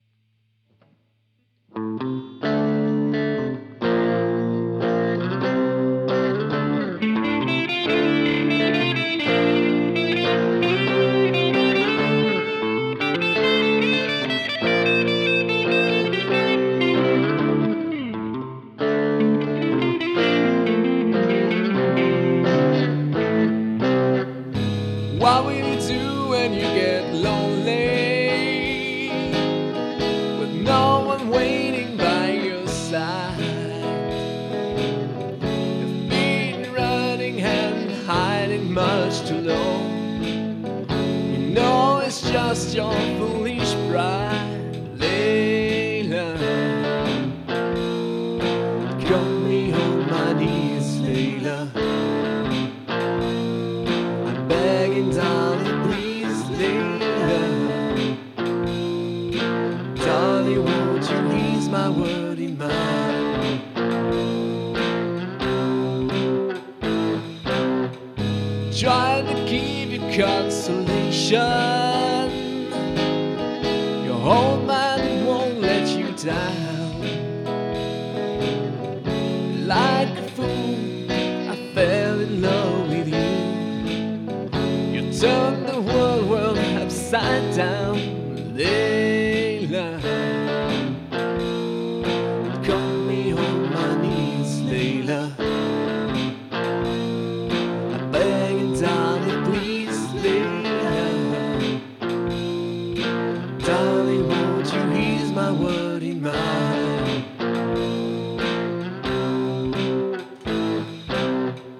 C'est un SM58.